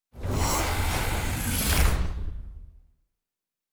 pgs/Assets/Audio/Sci-Fi Sounds/Doors and Portals/Door 1 Close 2.wav at master
Door 1 Close 2.wav